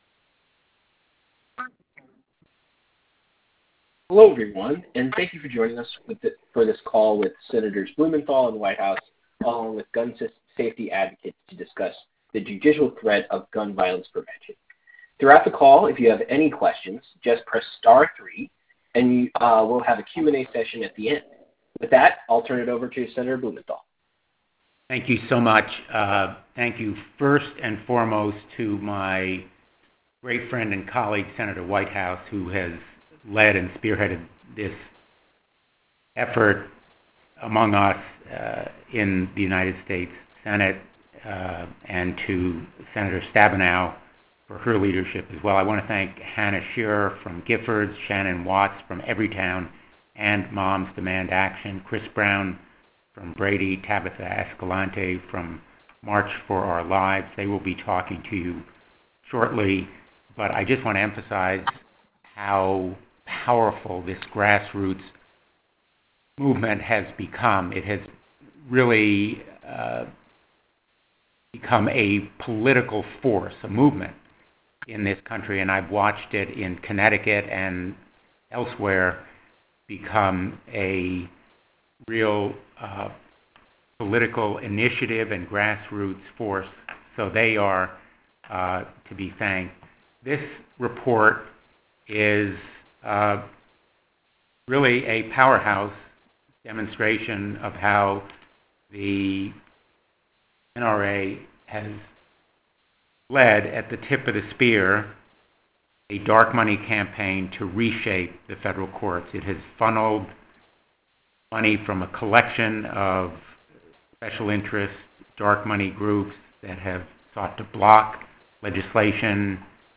The full recording of the press call is available here.